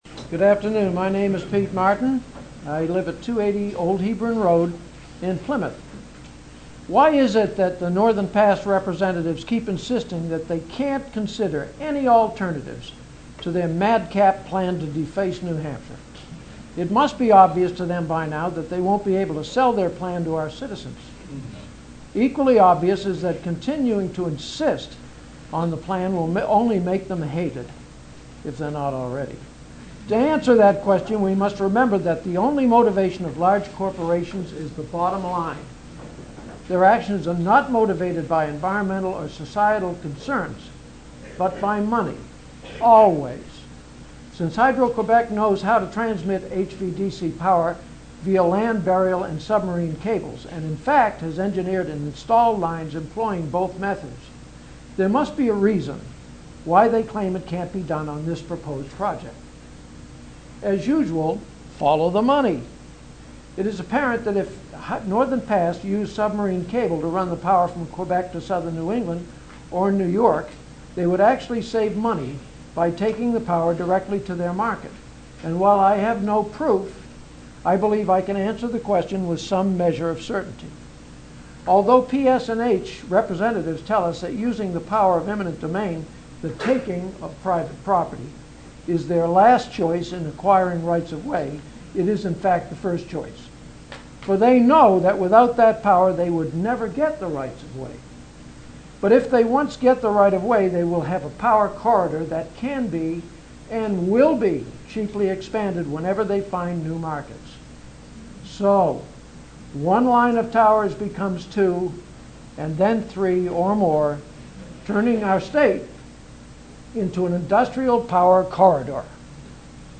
Public Scoping Meeting- Haverhill 3/20/11: